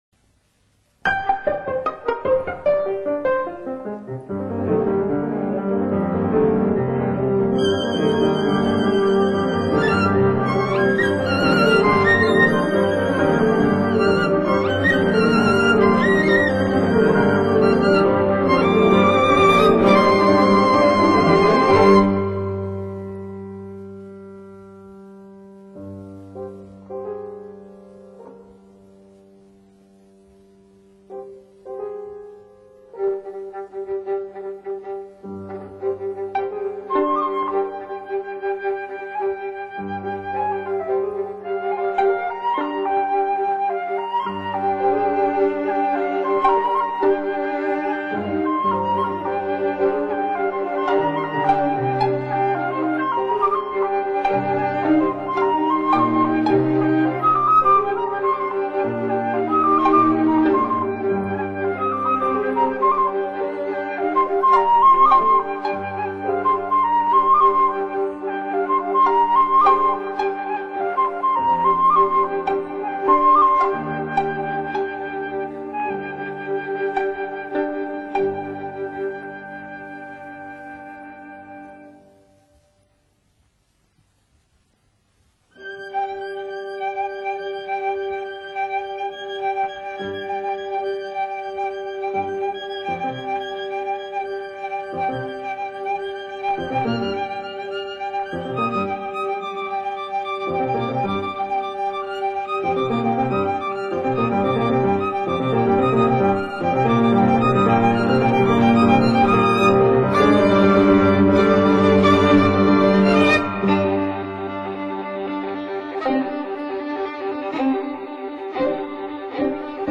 ورسیون موسیقی مجلسی